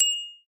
rollover.mp3